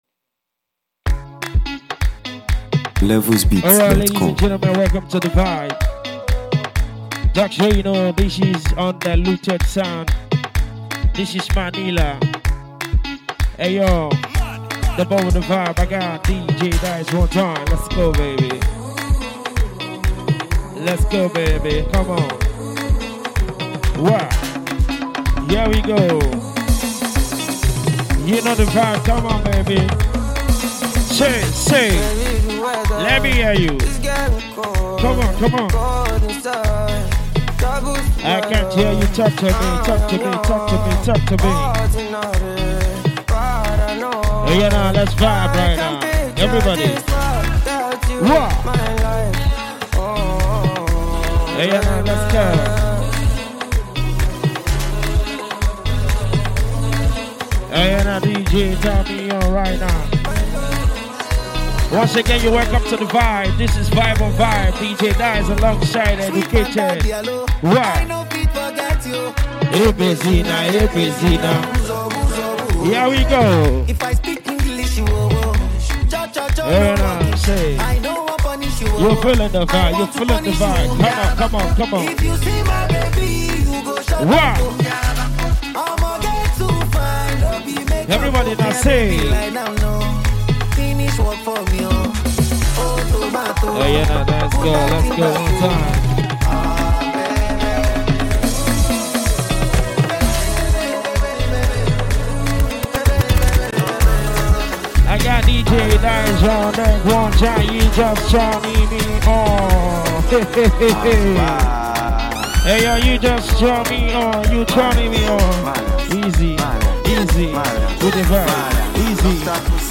Mp3 Download African songs
smooth Afrobeats and R&B flavor
live vocals and lyrical hype to elevate the mood